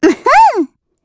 Estelle-Vox_Attack4_kr.wav